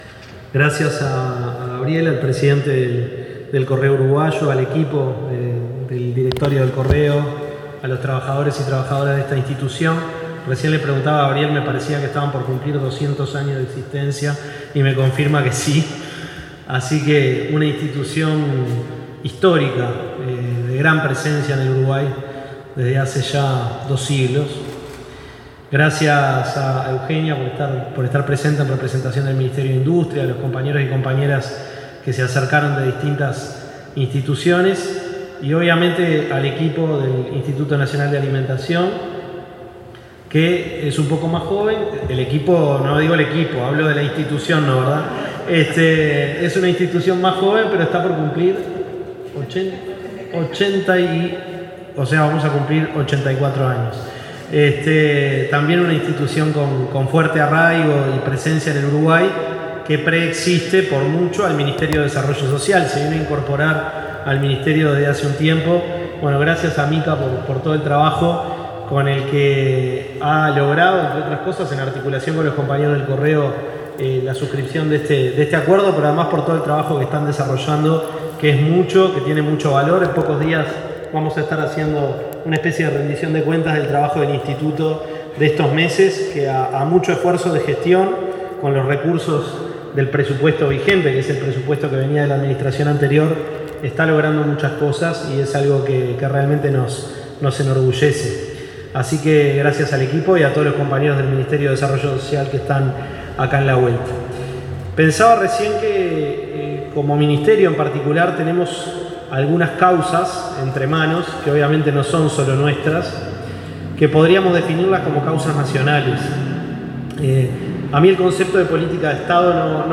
Palabras del ministro de Desarrollo Social, Gonzalo Civila
En la firma de un acuerdo interinstitucional entre el Correo Uruguayo y el Instituto Nacional de Alimentación (INDA), del Ministerio de Desarrollo